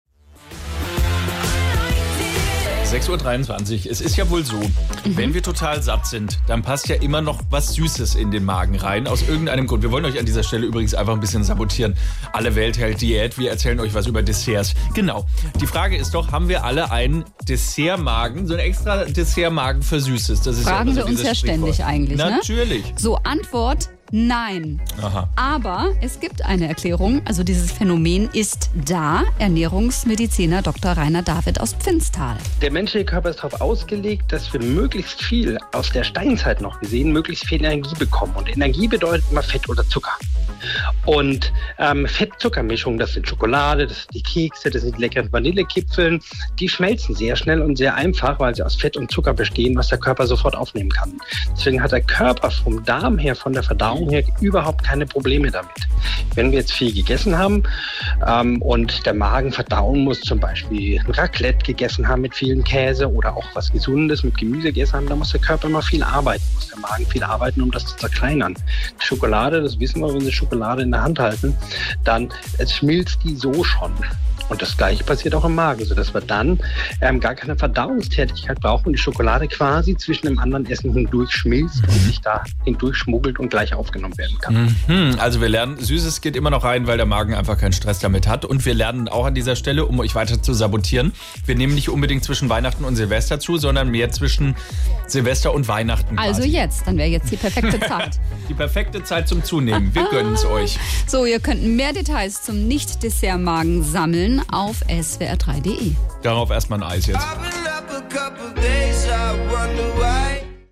In der SWR3 Morningshow